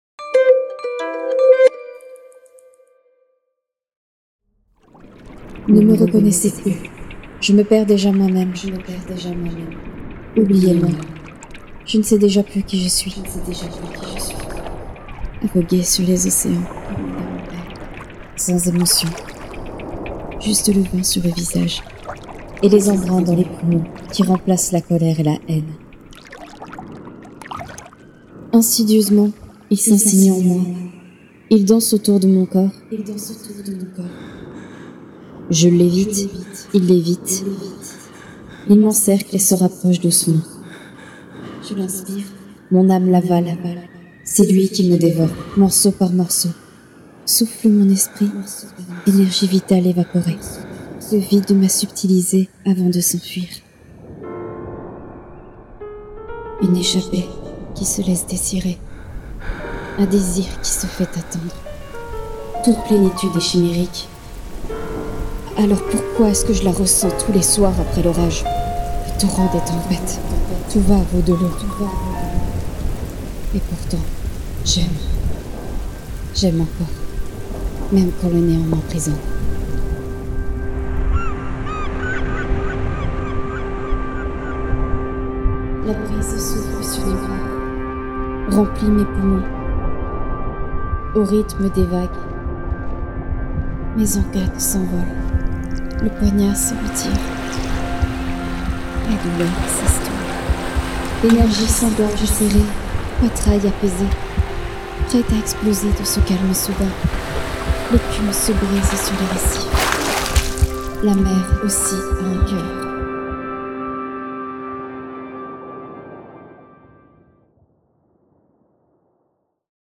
Cette expérimentation poético-sonore vous plonge au creux des vagues, où le flot de pensées côtoie le vide. Le texte a été écrit en août 2021 sous forme de 4 Twittoèmes maritimes et mis en son début 2022.
Poème
Musique libre de droit